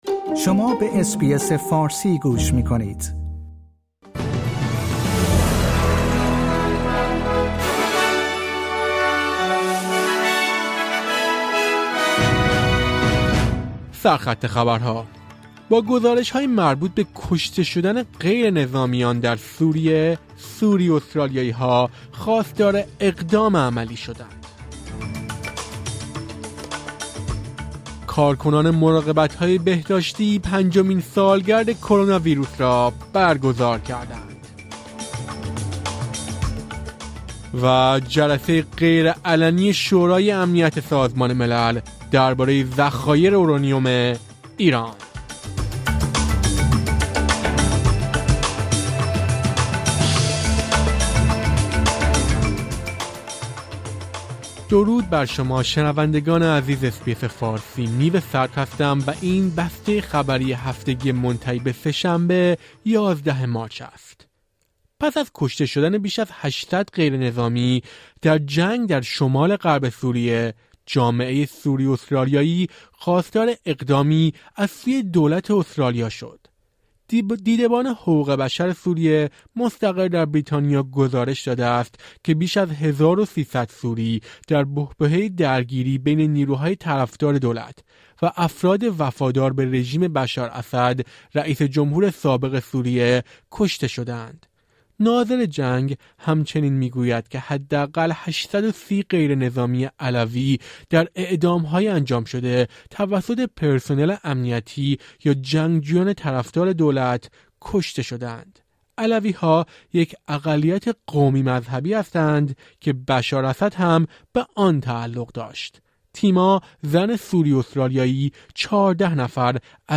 در این پادکست خبری مهمترین اخبار هفته منتهی به سه‌شنبه ۱۱ مارچ ارائه شده است.